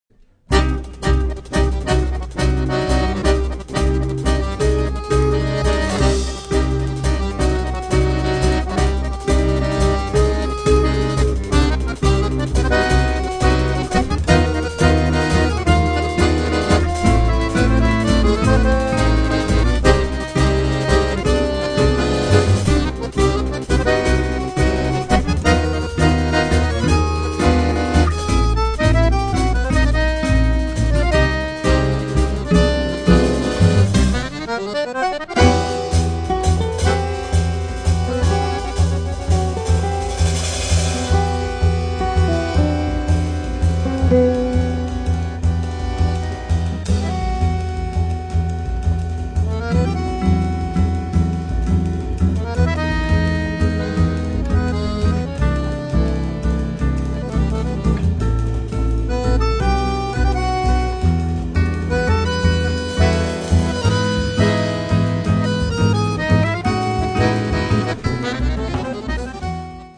chitarra
fisarmonica
contrabbasso
batteria